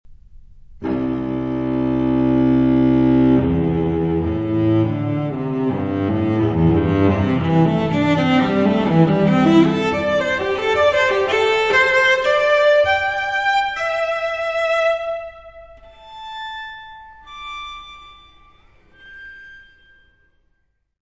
violoncelo.
: stereo; 12 cm
Área:  Música Clássica
Quasi cadenza (Quarter note = 80).